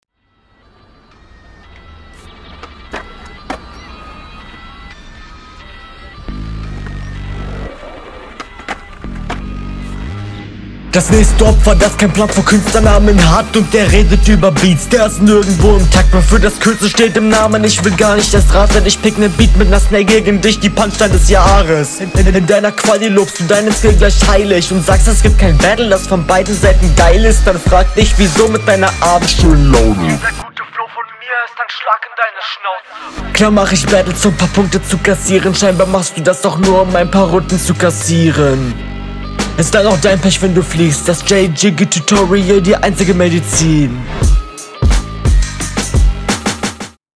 Hinrunde 1
Flow ist auf jeden Fall rund und onpoint.